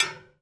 016Hitting alarm clock
描述：Blumlein stereo (MKH 30) recorded with AETA 4Minx inside a Parisian flat, edited (creating some clicks)
标签： plastic Hit object
声道立体声